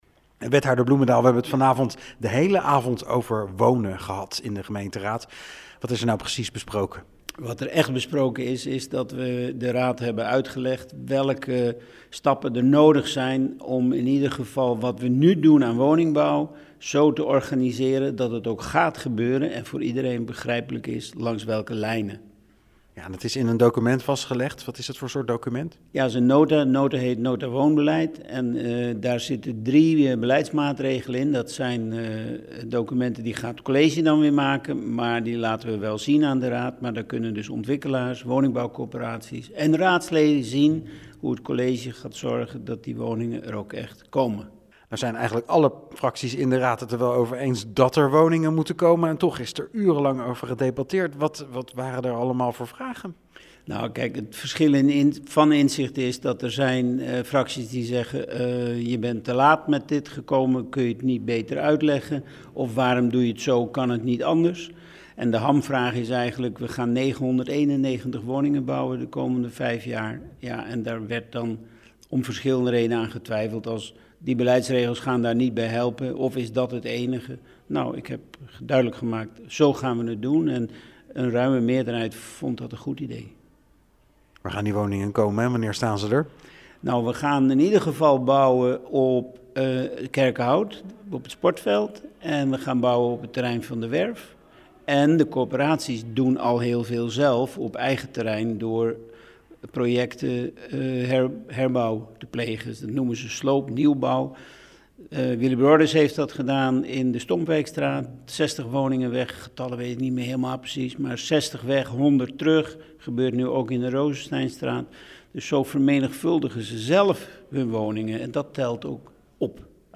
Wethouder Ritske Bloemendaal vertelt over het belang van het vastgestelde woonbeleid: